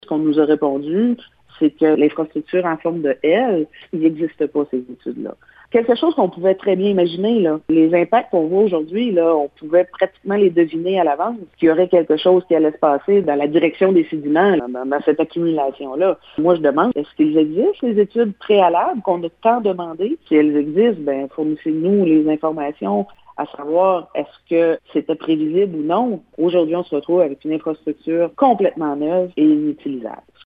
Cathy Poirier précise que sa municipalité n’a jamais reçu copie des études d’impact pour évaluer les effets de la nouvelle construction et qu’elle souhaite obtenir les documents existants si telle est le cas: